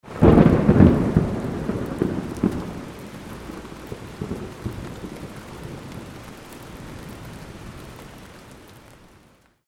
دانلود صدای رعد و برق از ساعد نیوز با لینک مستقیم و کیفیت بالا
جلوه های صوتی
برچسب: دانلود آهنگ های افکت صوتی طبیعت و محیط